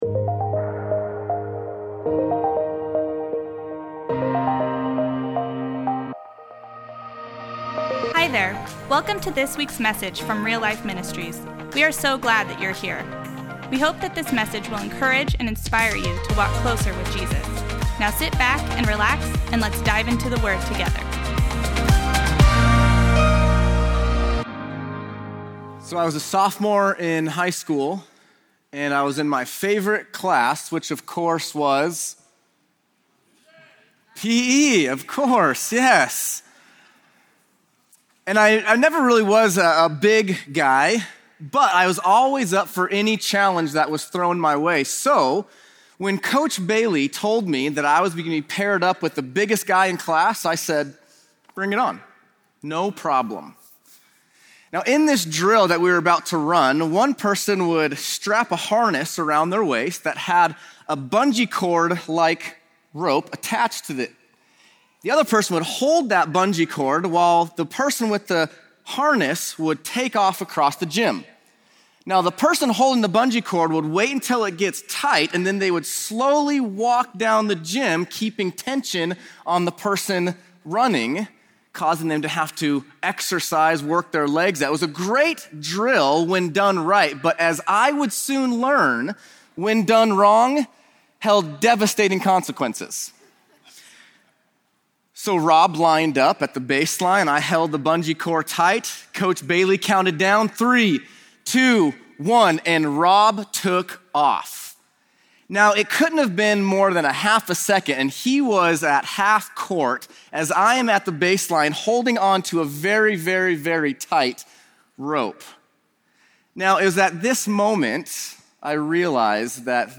SERIES INTRODUCTION • We are starting a sermon series this week called “Challenge Accepted,” and we are going to be talking about challenges that every follower of Jesus should accept. This series is about what God has to say about our stuff, possessions, and our money.